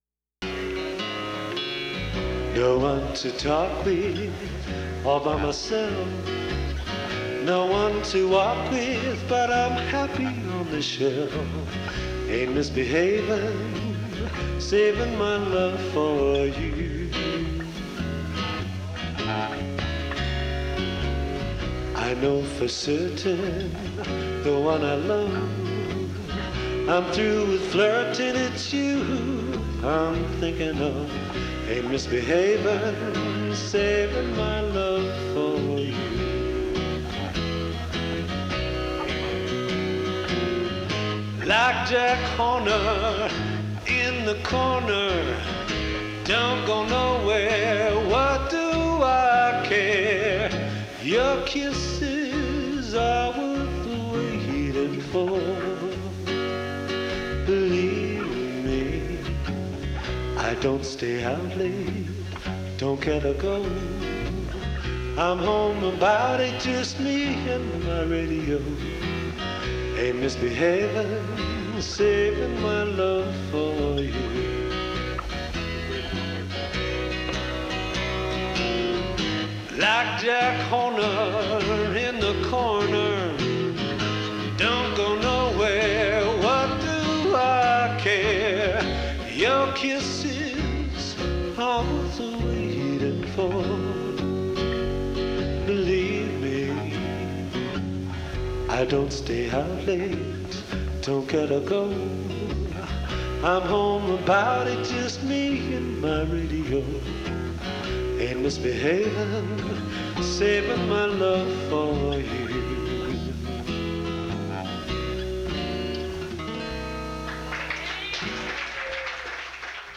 Category Rock/Pop
Studio/Live Live
guitar and vocals
bass, lead guitars and vocals
Recorded Live at the RED BARN, Peoria Il. Aug. 1989